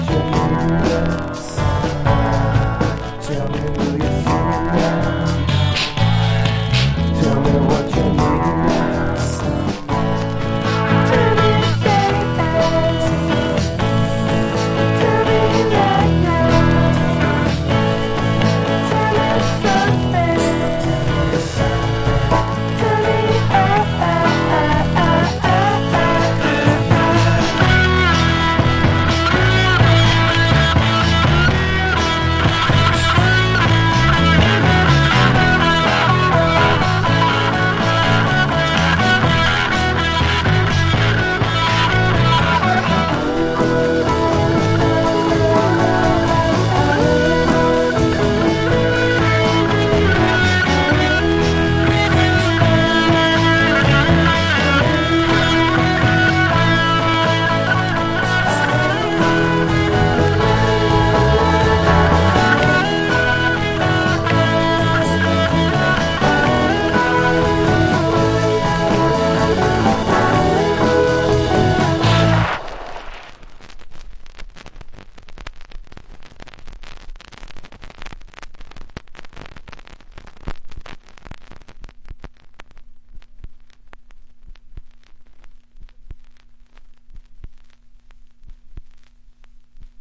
ポートランドのローファイ～ベッドルームポップ・バンド